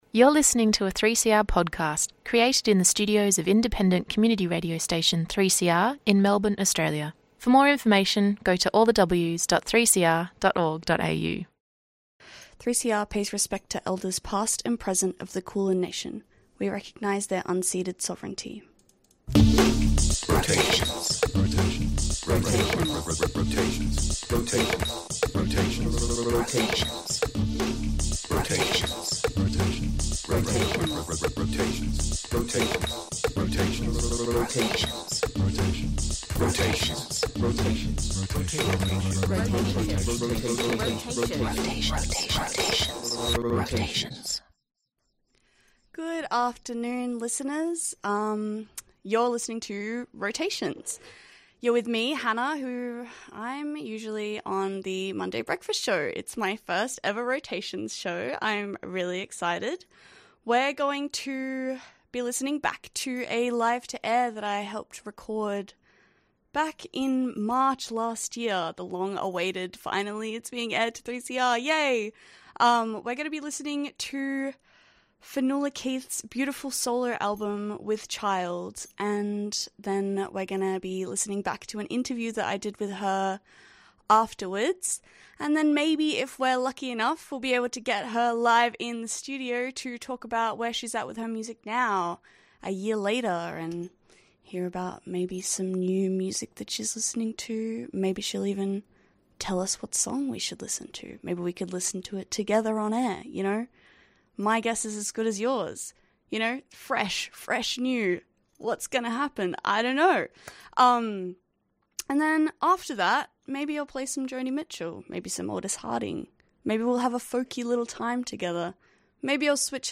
Live music guest